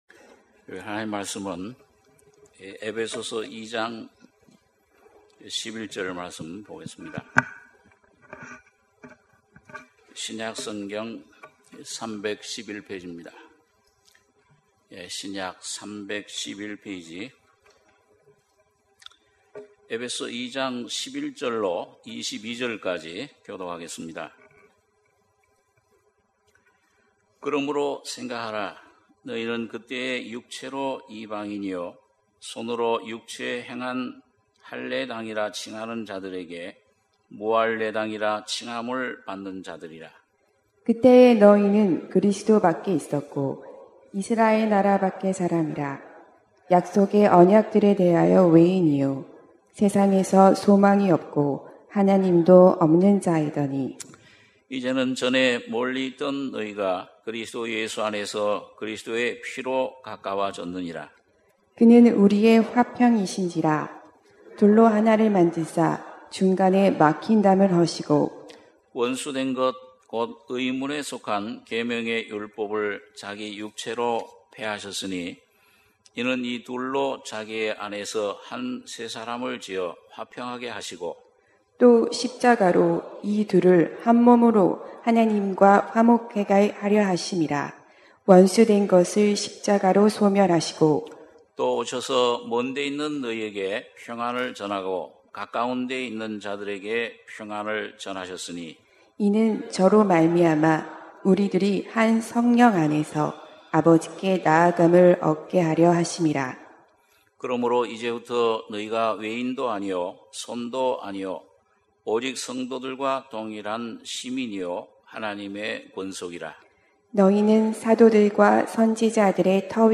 주일예배 - 에베소서 2장 11절~22절 주일1부